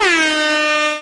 airhorn.wav